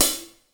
• Thin Pedal Hi-Hat Sample D Key 27.wav
Royality free pedal hi-hat sound tuned to the D note. Loudest frequency: 7433Hz
thin-pedal-hi-hat-sample-d-key-27-OYG.wav